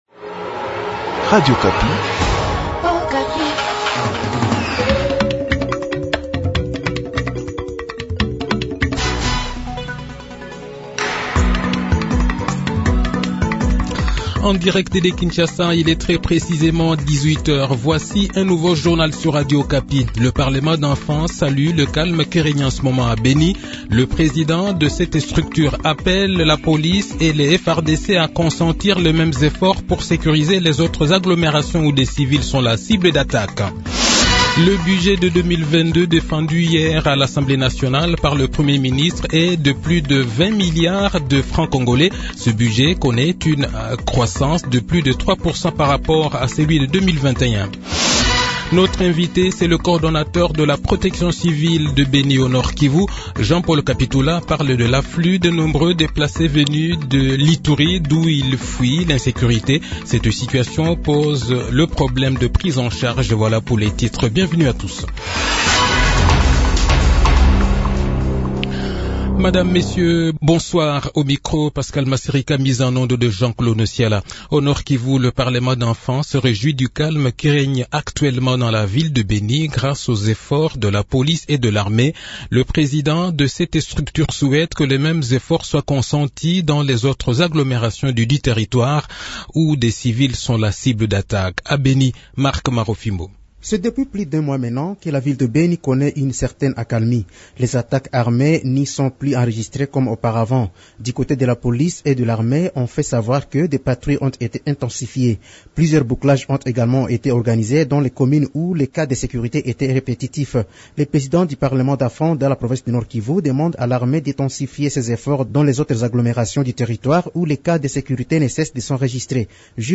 Le journal de 18 h, 16 novembre 2021